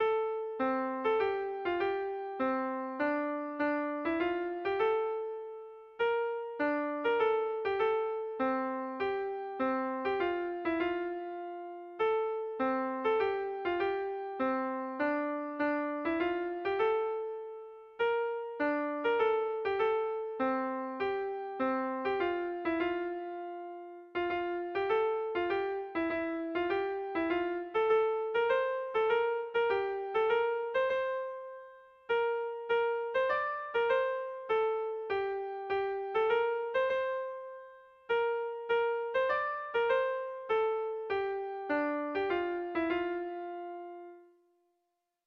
Sentimenduzkoa
ABD...